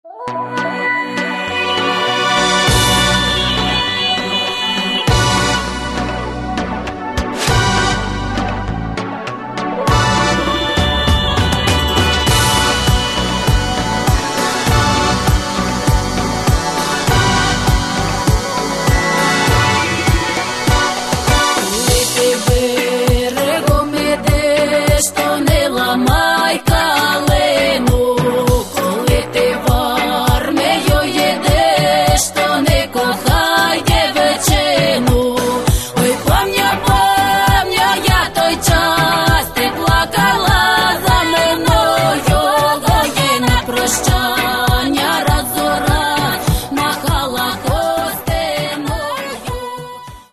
Каталог -> Народная -> Современные обработки